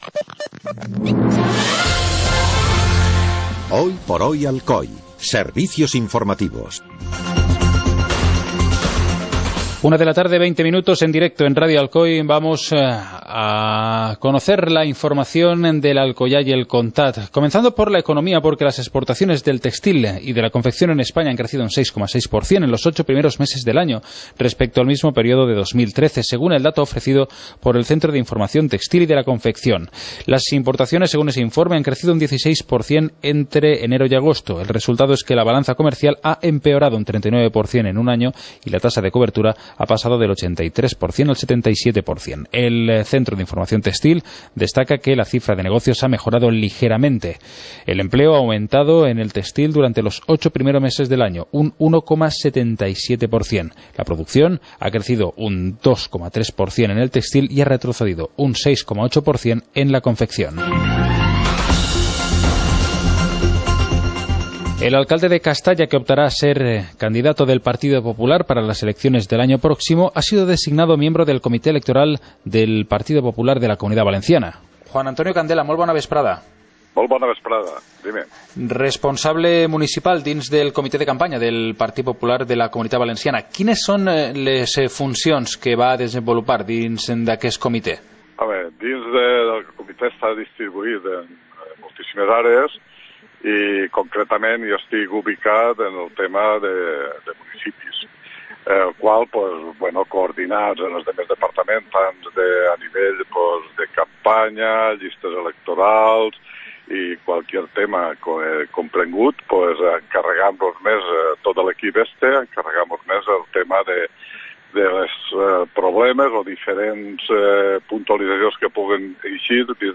Informativo comarcal - miércoles, 22 de octubre de 2014